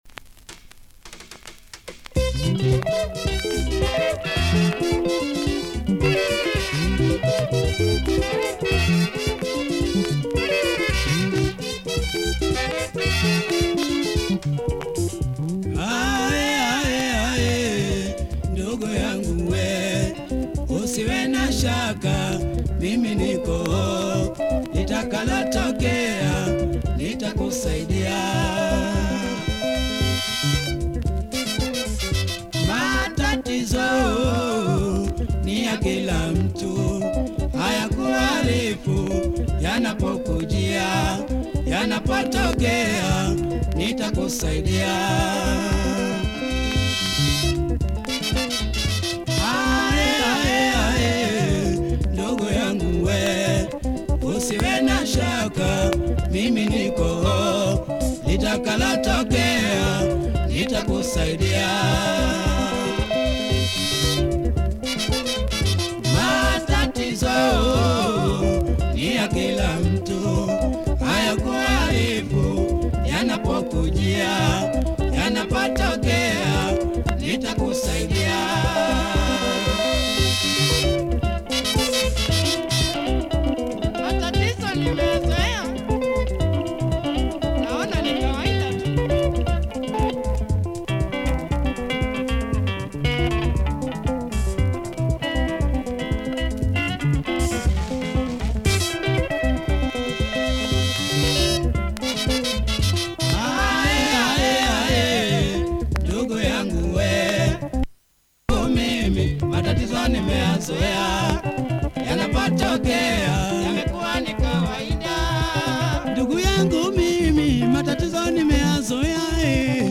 great drive and good guitar drive